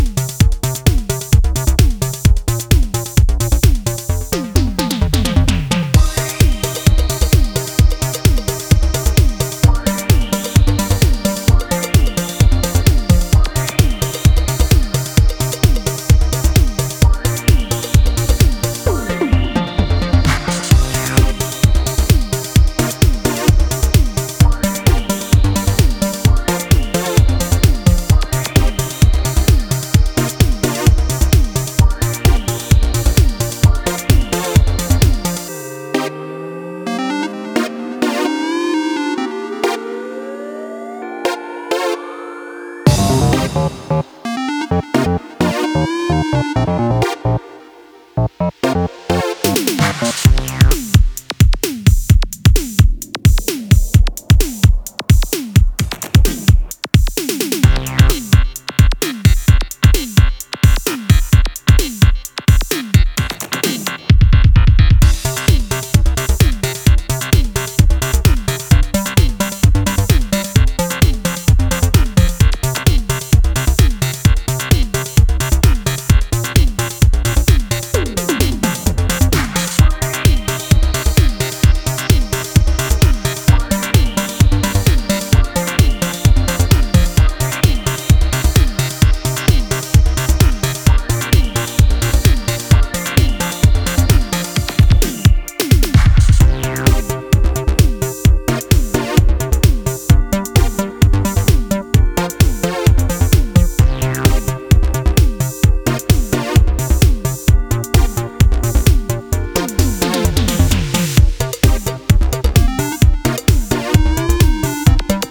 offering a rich blend of electronic sounds